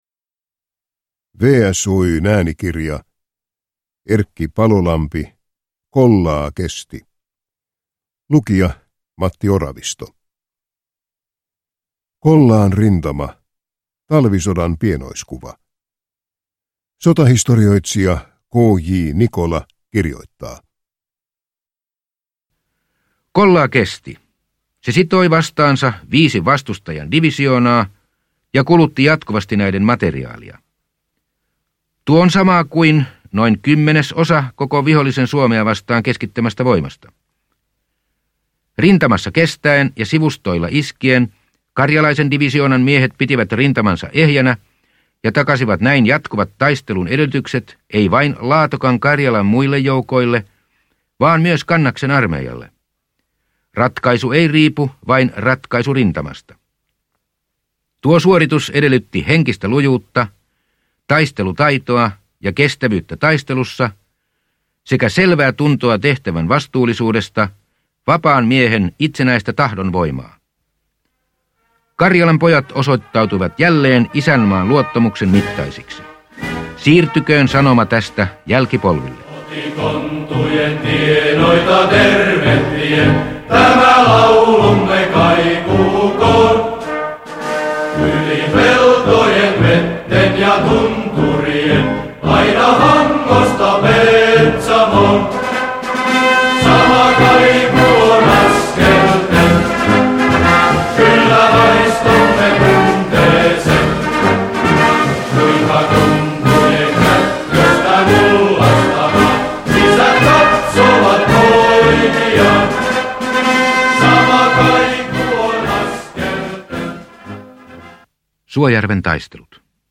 Kollaa kestää – Ljudbok – Laddas ner